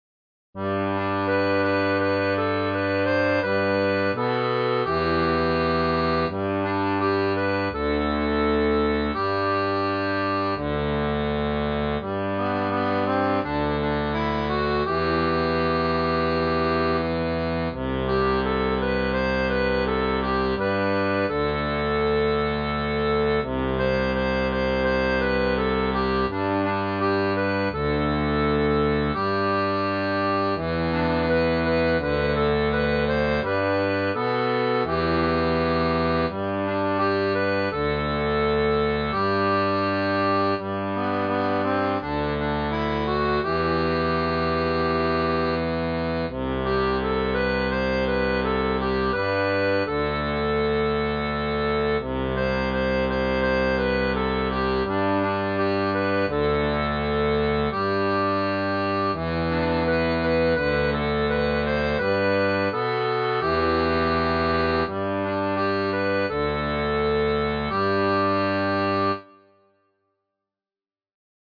• Une tablature pour diato à 3 rangs
Chanson française